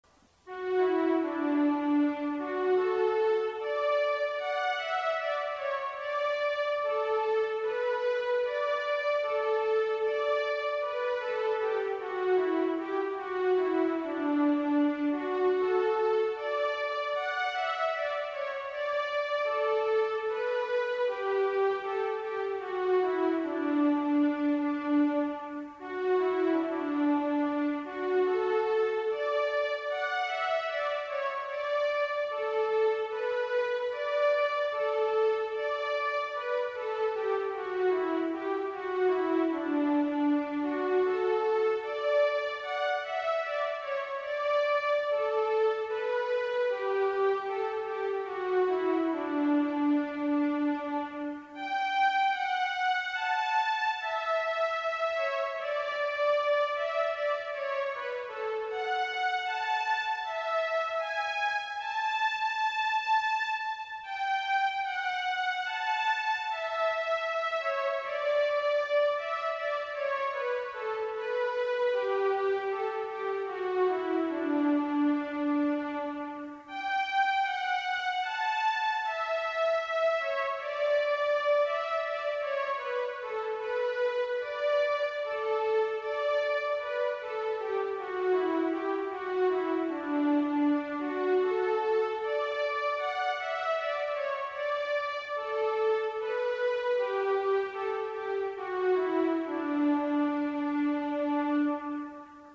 Here are a couple of tunes played on it:-